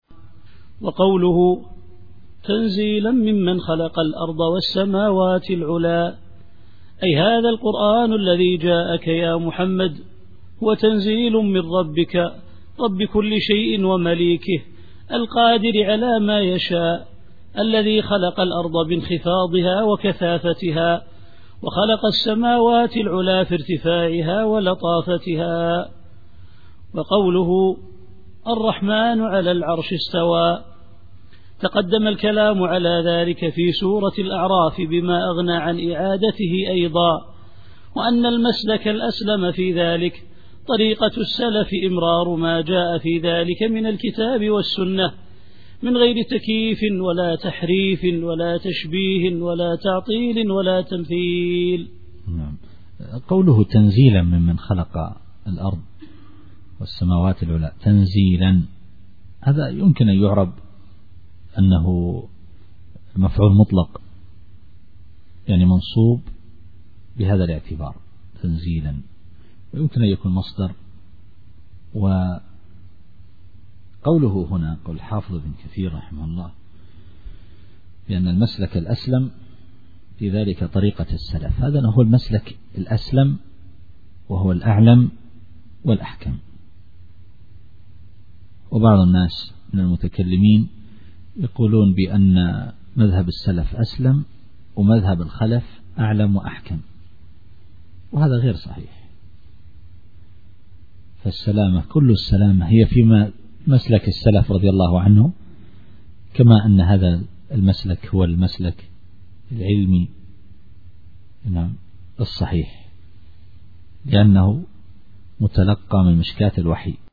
التفسير الصوتي [طه / 4]